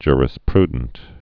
(jrĭs-prdnt)